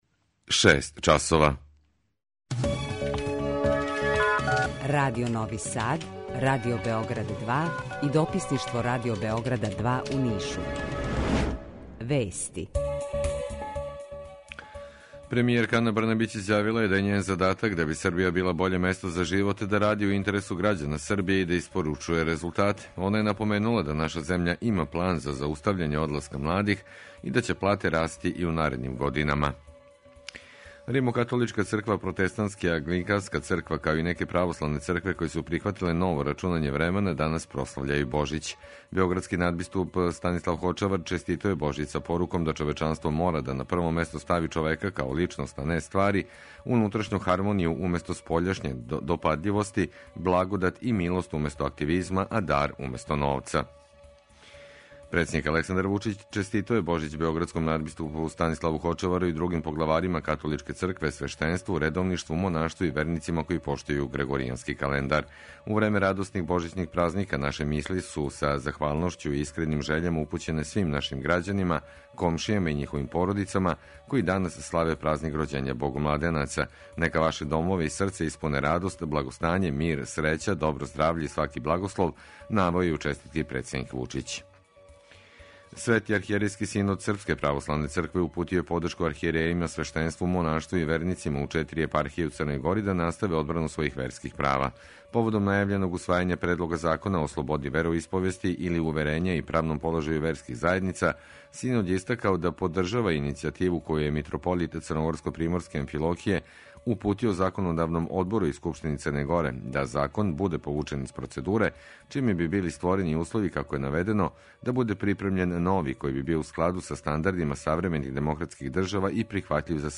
Гост Срђан Поповић, председник Скупштине општине Грачаница